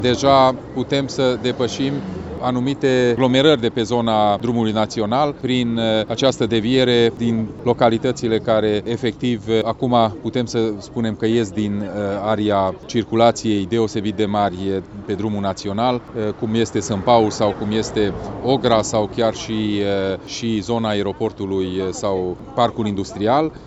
Președintele Consiliului Județean Mureș, Peter Ferenc, a arătat că această autostradă este crucială atât pentru dezvoltarea pe toate planurile a județului cât și pentru eliminarea presiunii traficului asupra locuitorilor de pe traseul Tîrgu-Mureș – Iernut.